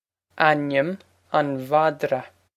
Pronunciation for how to say
an-im on VOD-ra
This is an approximate phonetic pronunciation of the phrase.